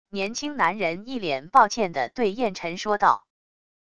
年轻男人一脸抱歉地对晏晨说道wav音频生成系统WAV Audio Player